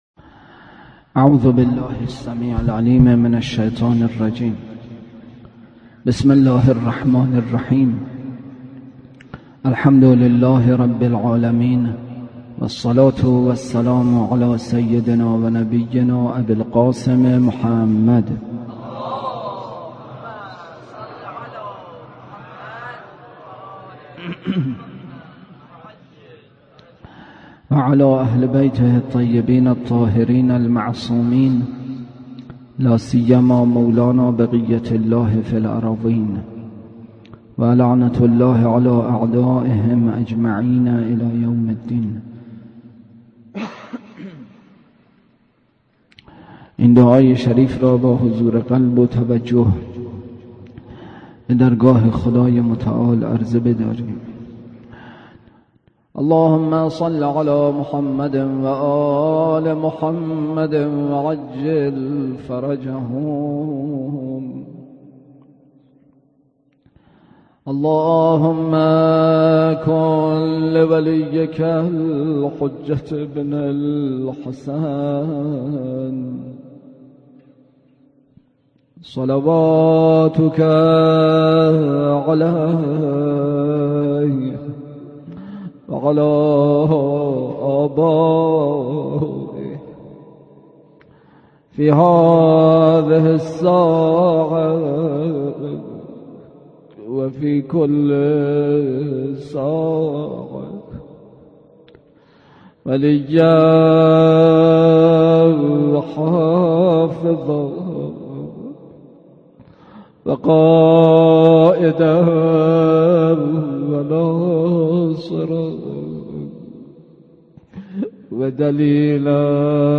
بحث اصلی: شرح وصیّت امام باقر(ع): معنا و محدوده‌ی زهد، قصر امل، عجب و راه‌حلّ آن، معرفت نفس، راحت جان و تقوی پاسخ امام صادق(ع) به متصوّفه در امر رزق، کاستن از خطایا روضه: روضه‌ی جناب قاسم‌ابن الحسن(ع)
سخنرانی